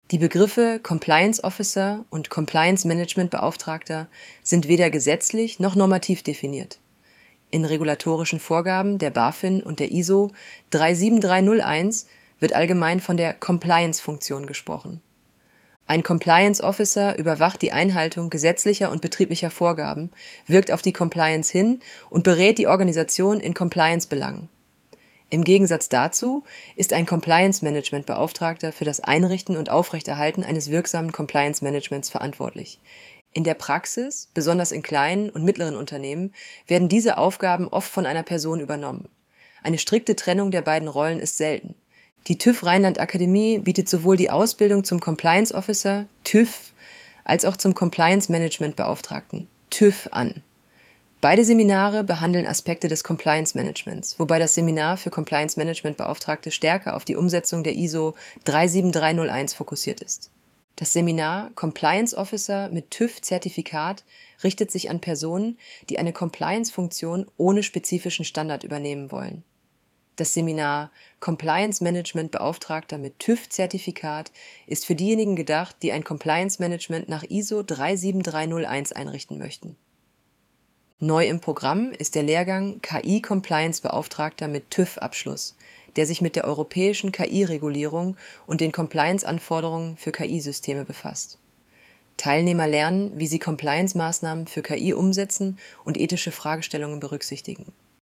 Hinweis: Diese Vorlesefunktion verwendet eine synthetisch erzeugte Stimme aus einem KI-System.Die Stimme ist keine Aufnahme einer realen Person.